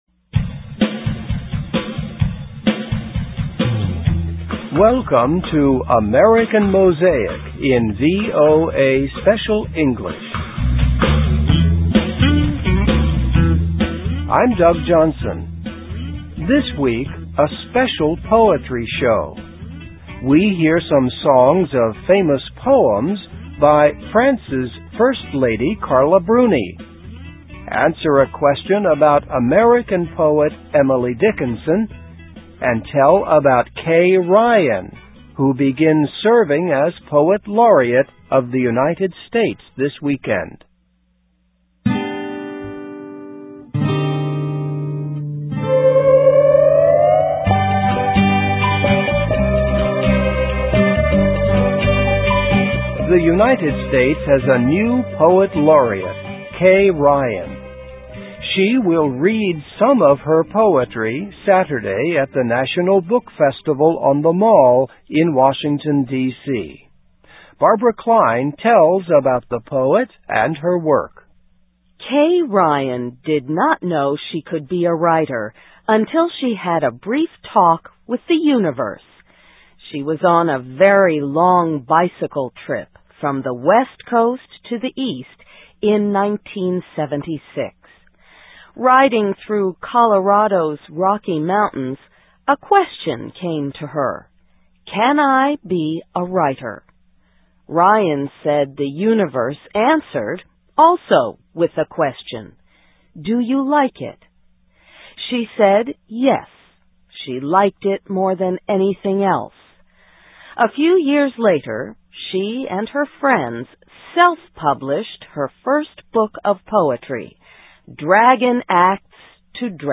Listen and Read Along - Text with Audio - For ESL Students - For Learning English
Welcome to AMERICAN MOSAIC in VOA Special English.